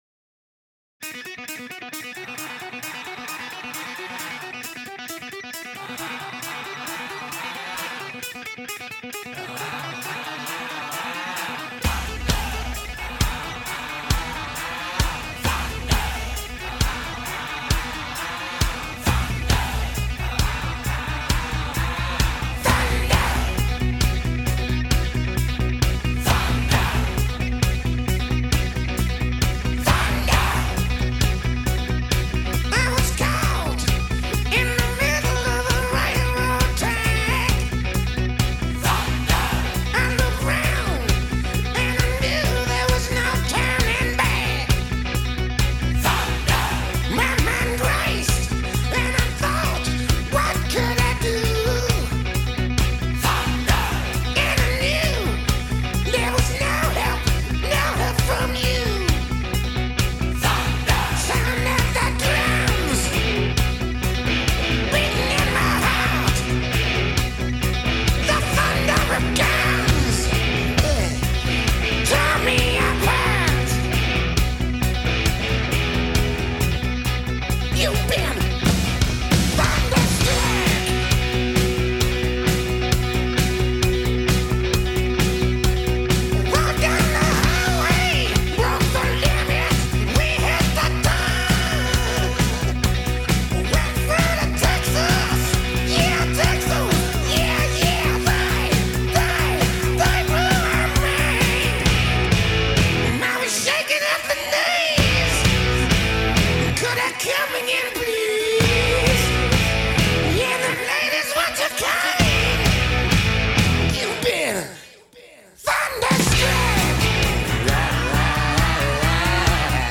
Kick
Original artist cut-down